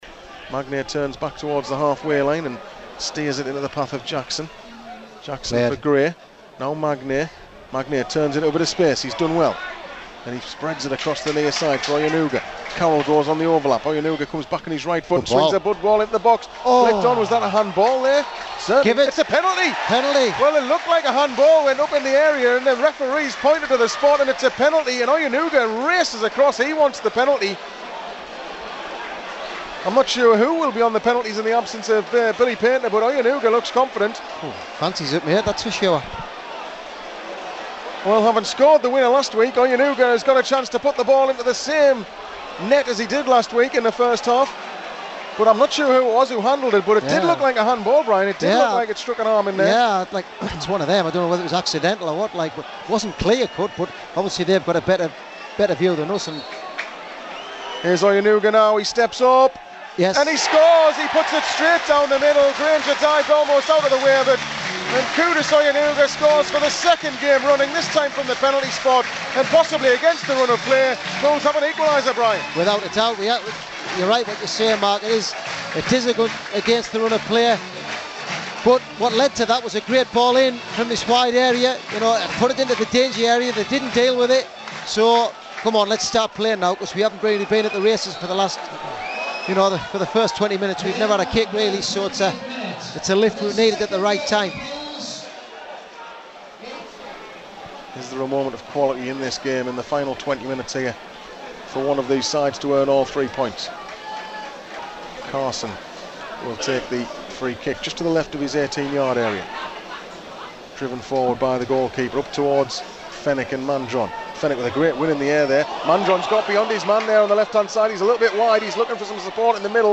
Listen back to how the goals from Sunday's win sounded as they went in live on Pools PlayerHD.